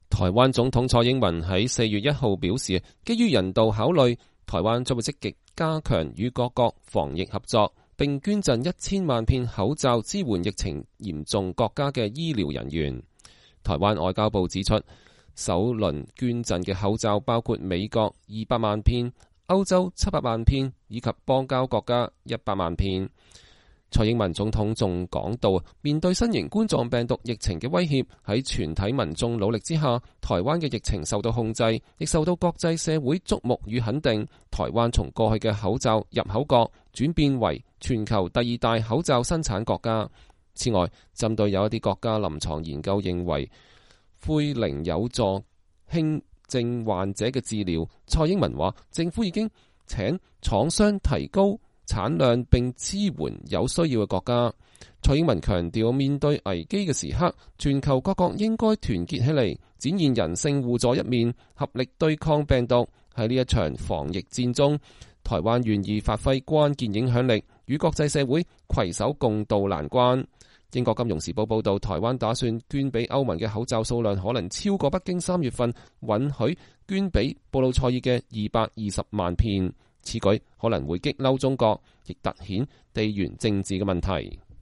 蔡英文2020年4月1日在記者會上發表講話。